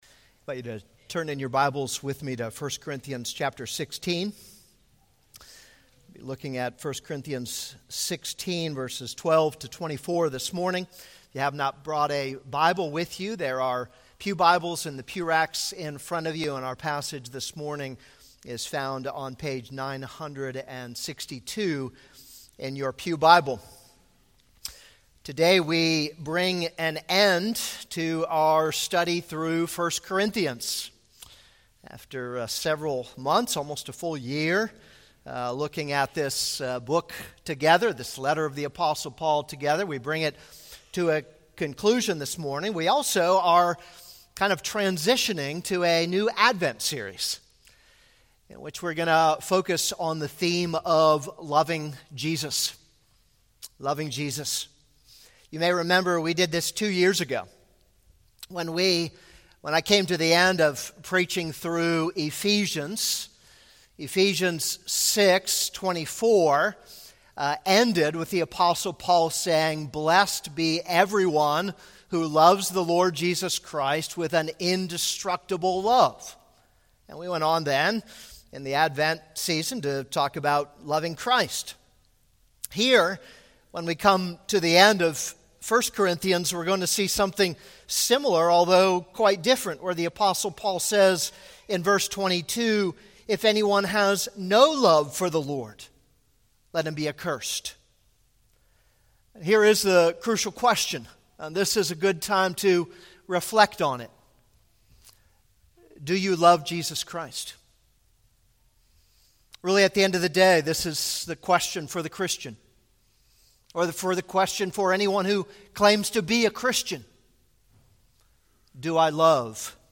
This is a sermon on 1 Corinthians 16:12-24.